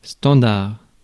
Ääntäminen
France (Paris): IPA: [stɑ̃.daʁ]